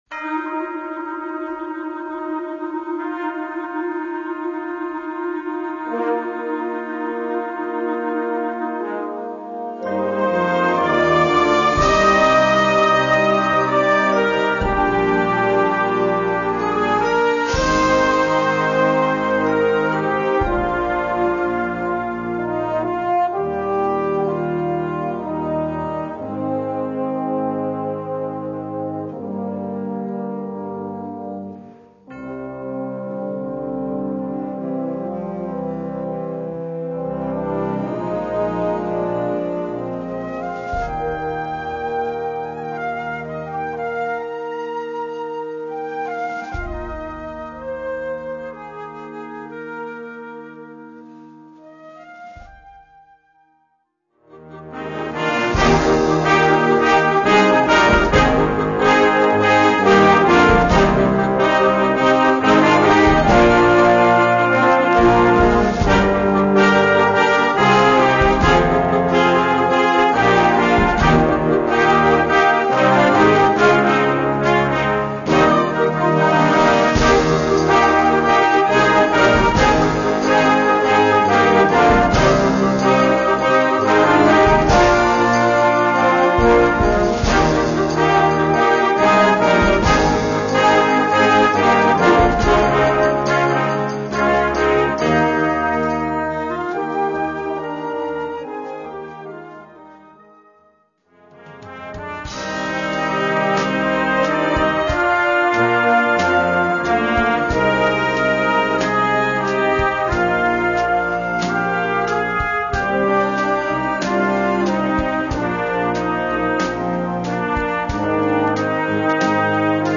Gattung: Medley für Jugendblasorchester
Besetzung: Blasorchester
concert band